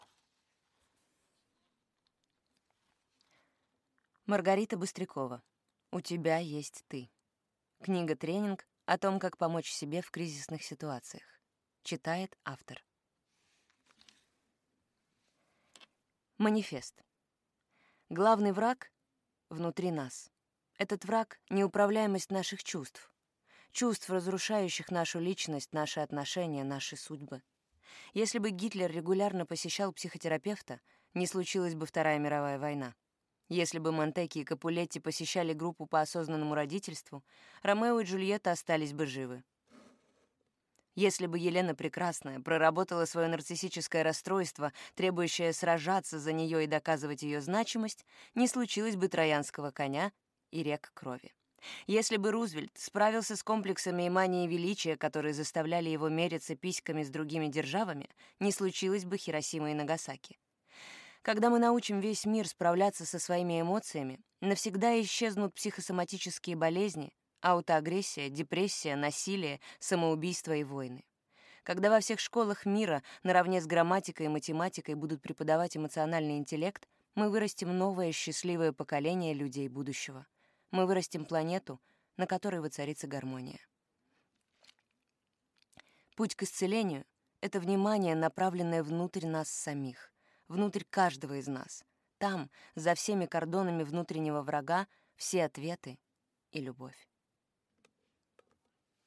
Аудиокнига У тебя есть ты. Медитация 2. Как понять себя. Практика наблюдения за собой | Библиотека аудиокниг